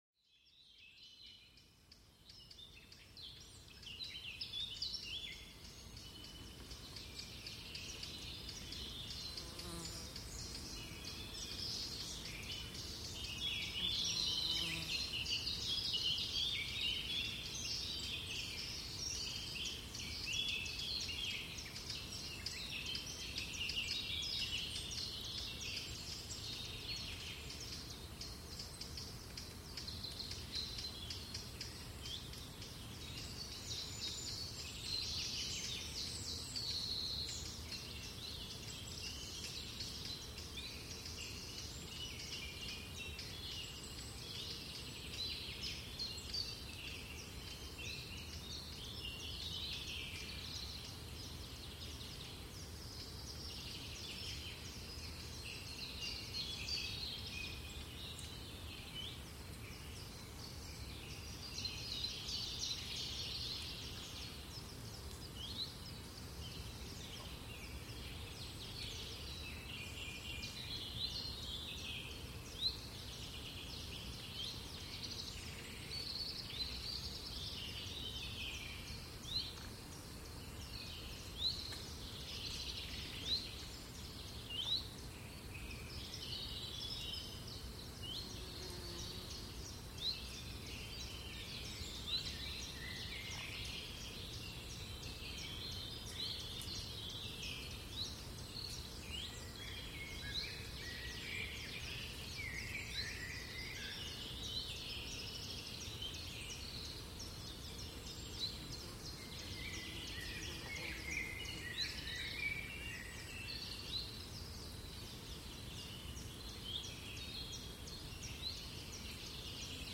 Sons apaisants des oiseaux en forêt pour une relaxation profonde